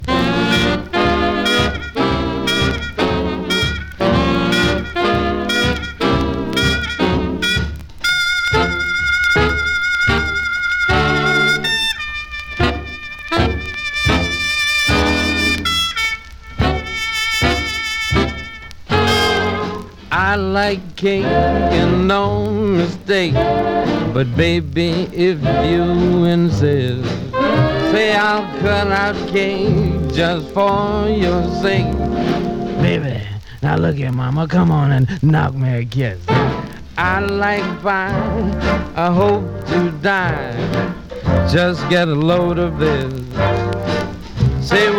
軽やかさとナイトクラブの喧騒を感じるようなミッド・テンポな楽曲がずらり。
Jazz, Big Band, Swing　USA　12inchレコード　33rpm　Mono